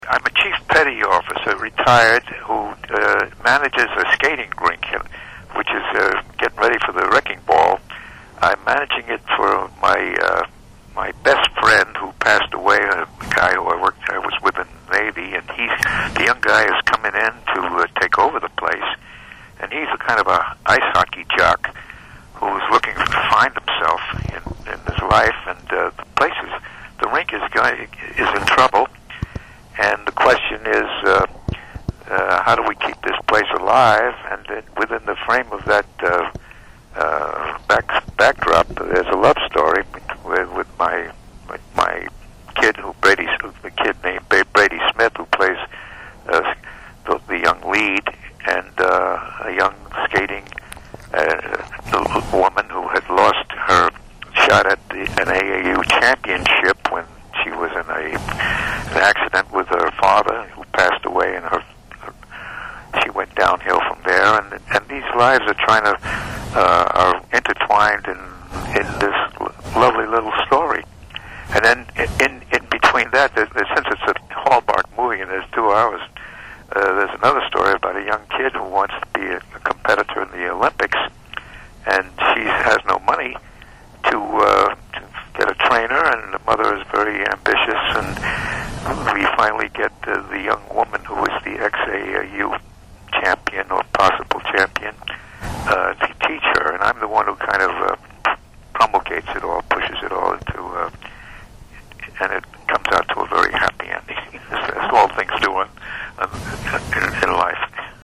Who would have imagined that George Costanza's father and Doug Heffernan's father-in-law would turn out to be such a wonderful, soft-spoken, interesting person?
Interview